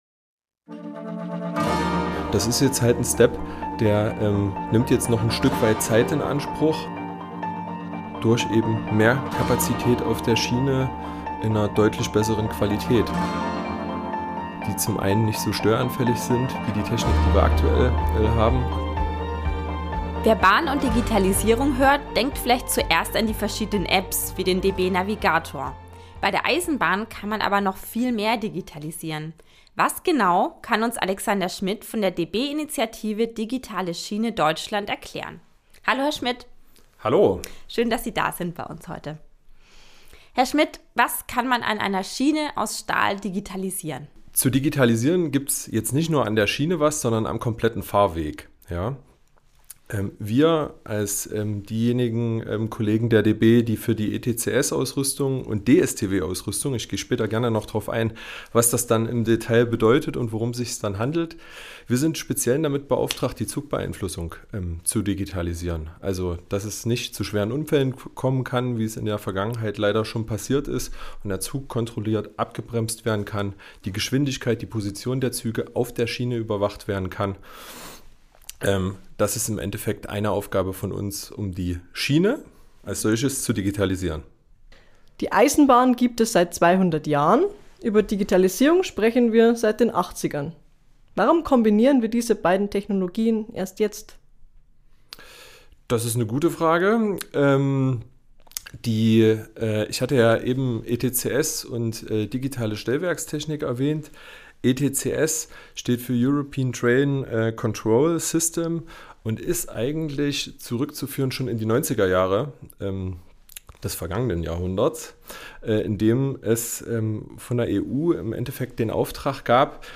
Die Initiative Digitale Schiene Deutschland (DSD) bringt diese beiden Technologien zusammen. Ein Gespräch